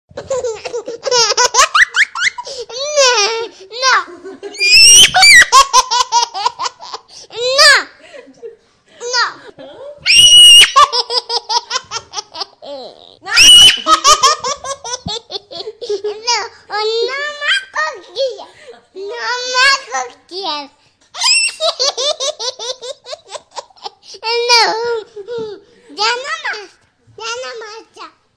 Baby Laughing Sound Effects ringtone free download